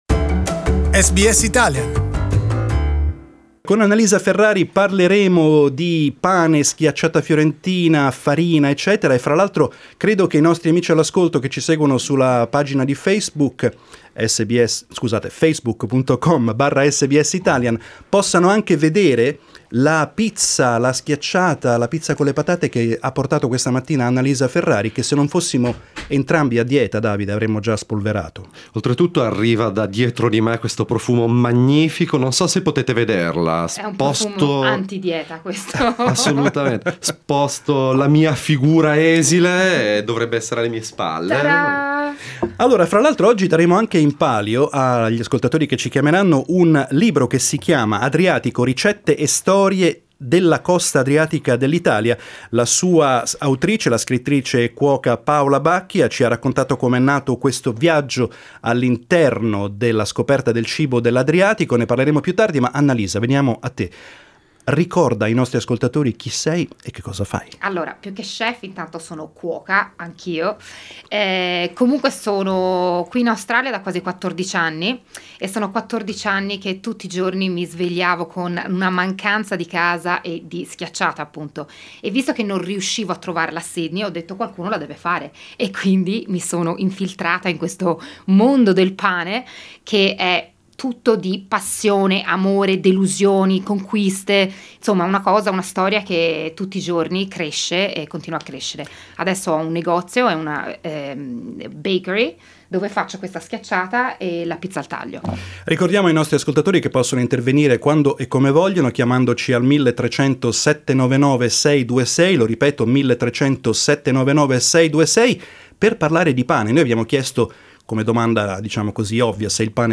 Our Tuesday morning talkback is dedicated to bread and how to make it.